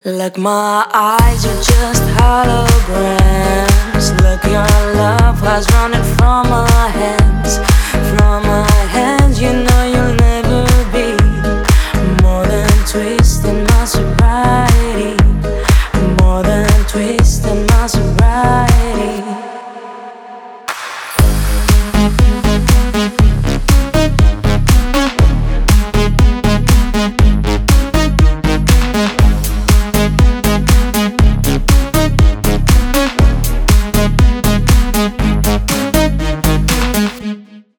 • Качество: 320 kbps, Stereo
Поп Музыка
клубные
кавер